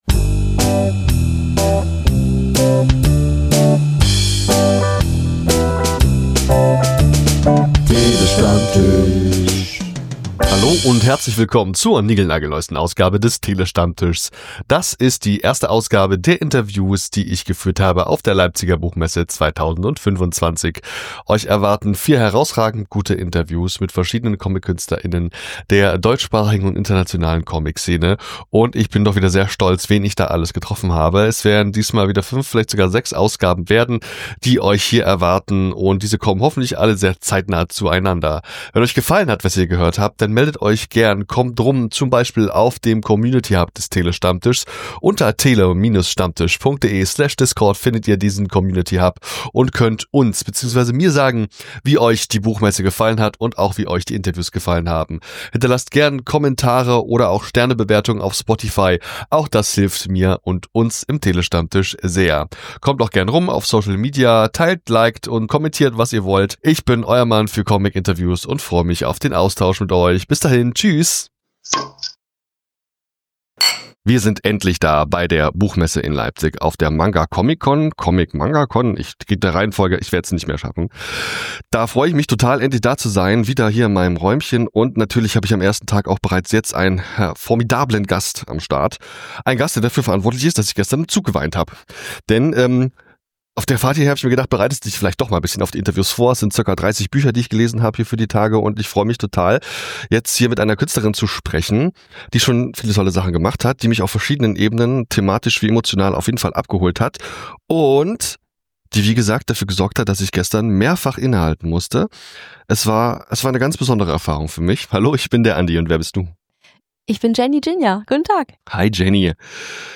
Natürlich waren wir auch 2025 wieder mittendrin im Messetrubel der Leipziger Buchmesse und Manga-Comic-Con. In der ersten Folge unserer Messe-Specials haben wir deshalb jede Menge spannende Interviews für dich im Gepäck.